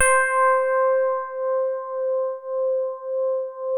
FINE HARD C4.wav